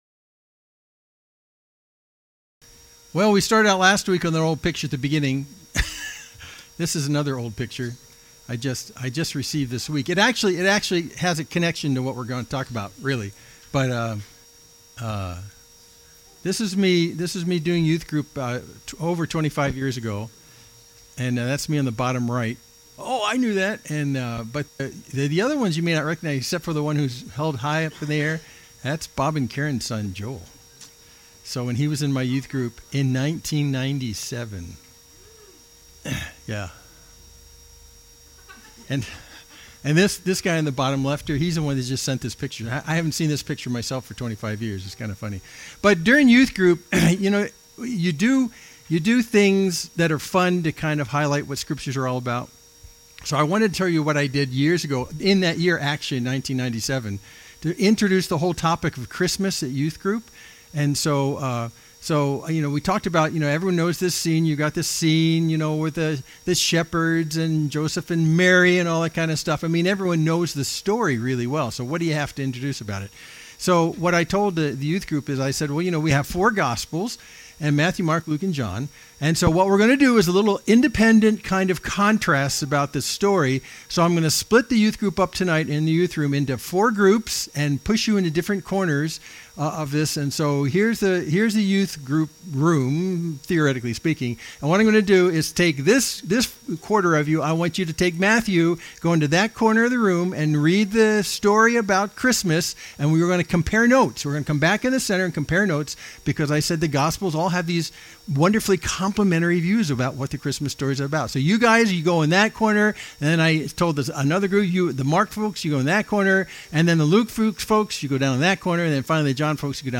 Main Street Church Sermon (17.48 - )